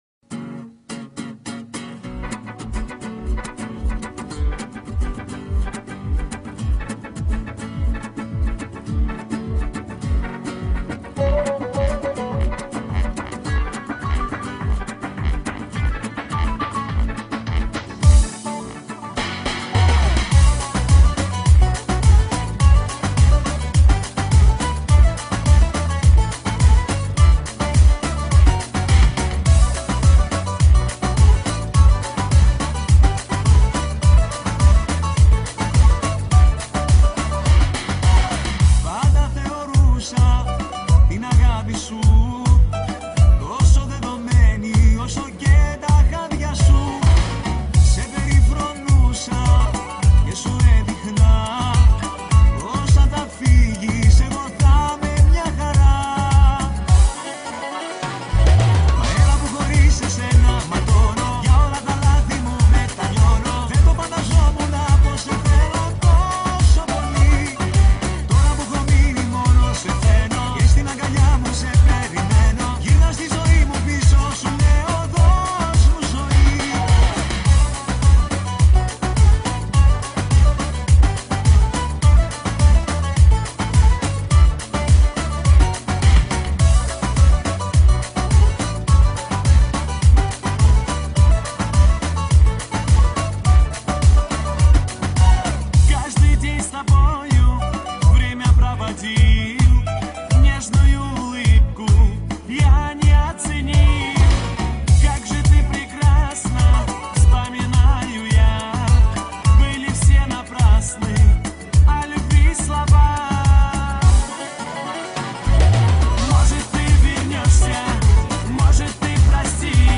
Категория: Восточная музыка » Армянские песни